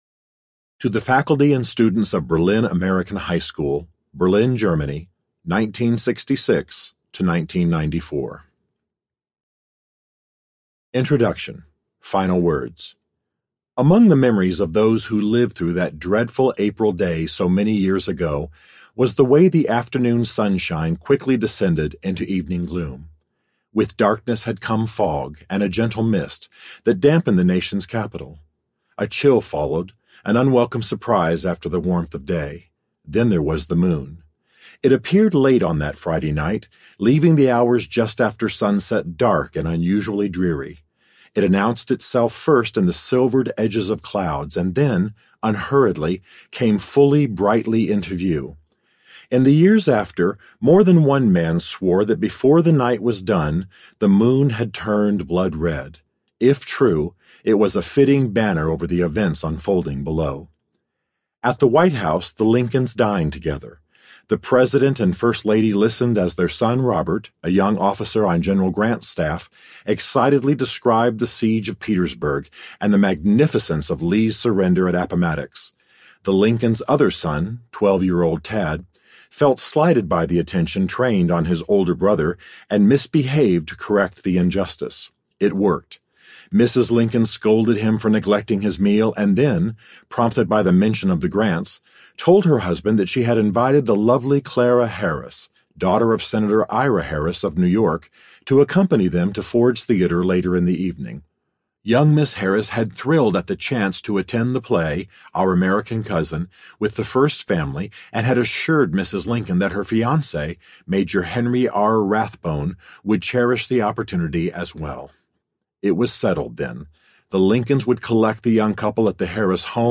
Lincoln’s Battle with God Audiobook
Narrator
Stephen Mansfield